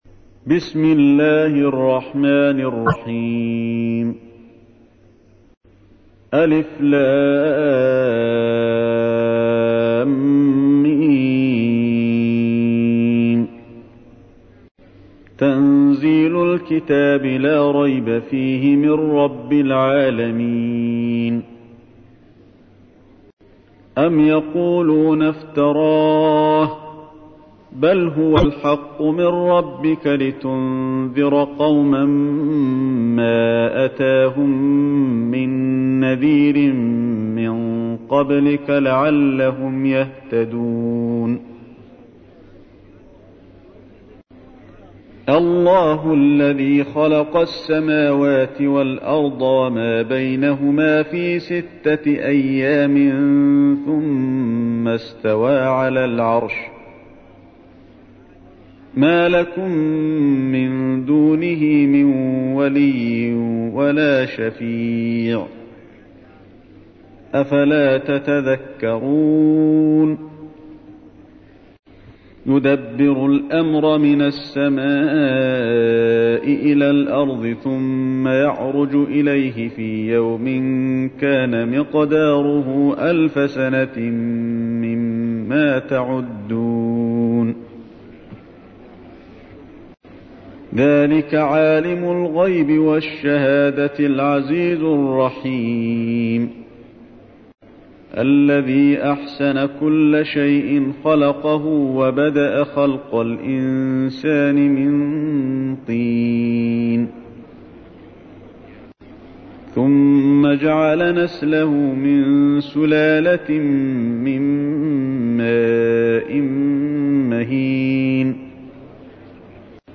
تحميل : 32. سورة السجدة / القارئ علي الحذيفي / القرآن الكريم / موقع يا حسين